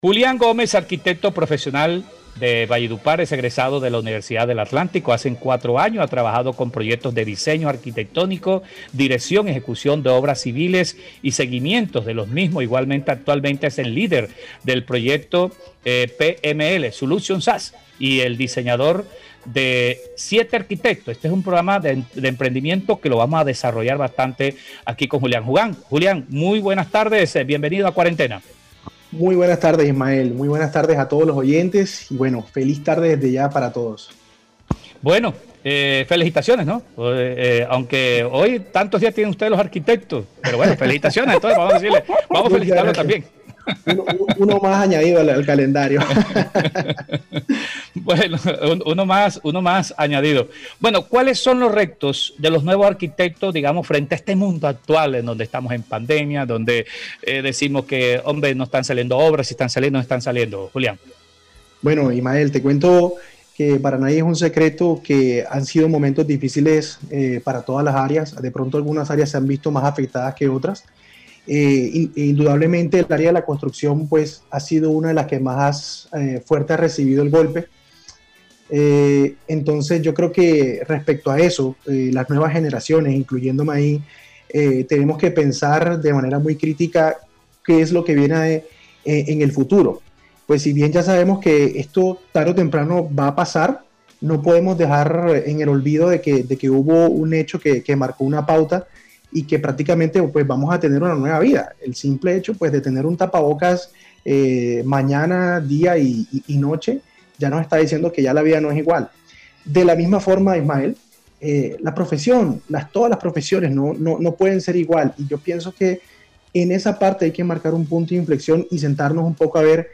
En el programa Cuarentena del Sistema Cardenal, contó que para él ha sido un sueño crear su propia firma de arquitectura, más con este nuevo tiempo de pandemia.